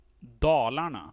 Dalarna (Swedish: [ˈdɑ̂ːlaɳa]
Sv-Dalarna.ogg.mp3